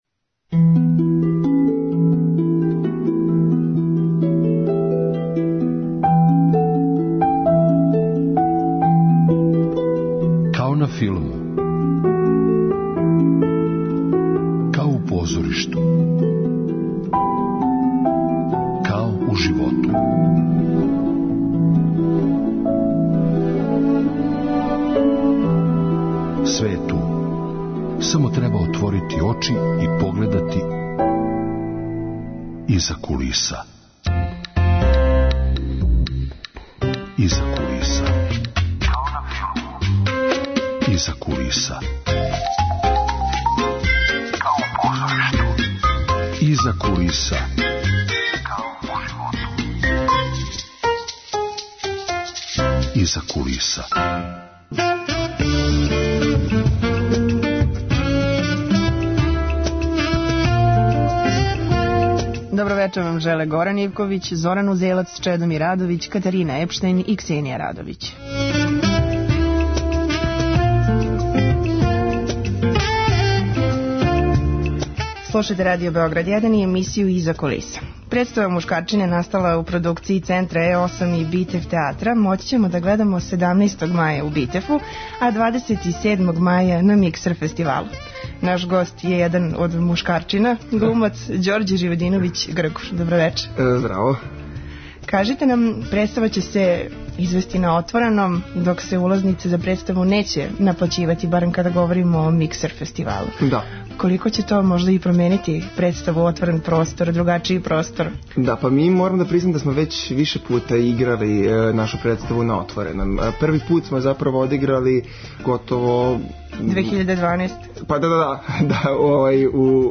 Емисија о филму и позоришту.